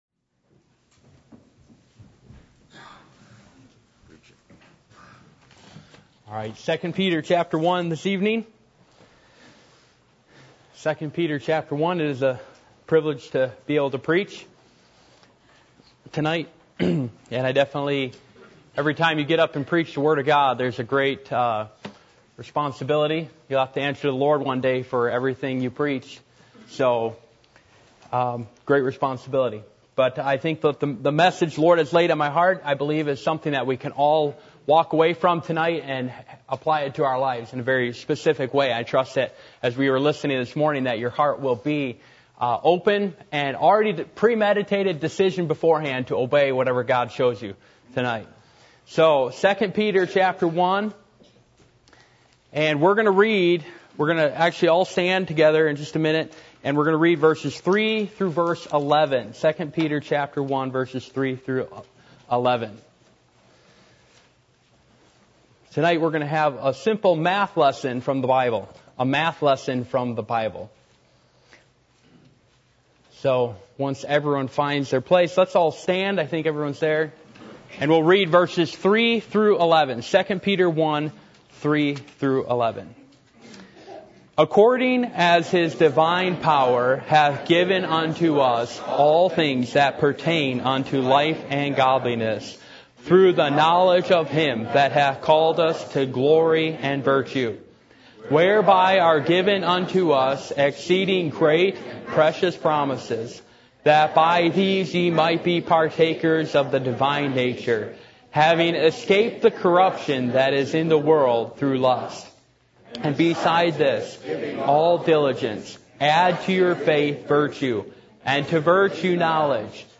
Passage: 2 Peter 1:3-11 Service Type: Sunday Evening %todo_render% « The Holy Spirit